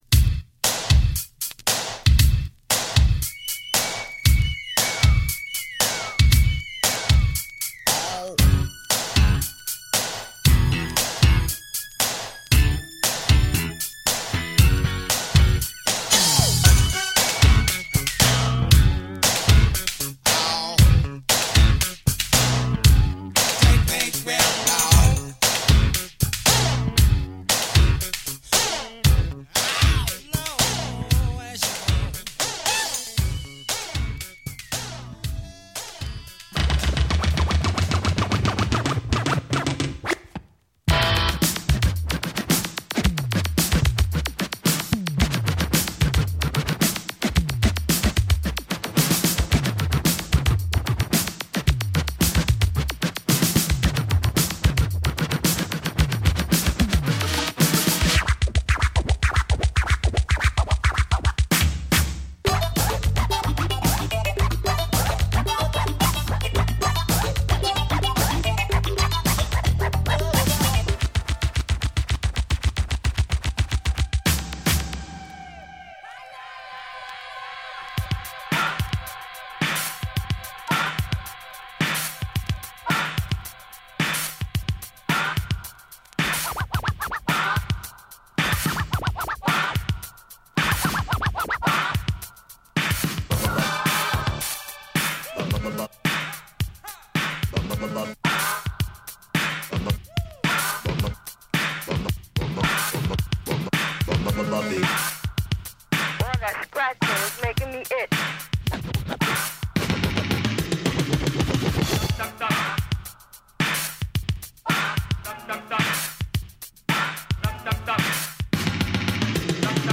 25 80s hip hop and rap, plus some pop tunes inspired by hip hop
We are taking a strange trip into the world of 80s rap and hip-hop. Warning: contains middle class white people trying to rap
80s-hip-hop.mp3